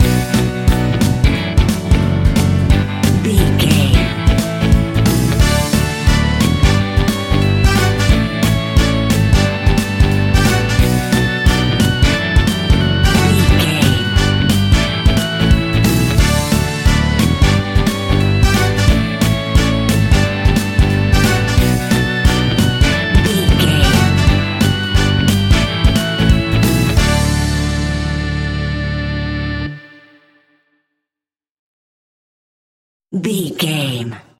Fast paced
Uplifting
Ionian/Major
D
pop rock
indie pop
fun
energetic
acoustic guitars
drums
bass guitar
electric guitar
piano
electric piano
organ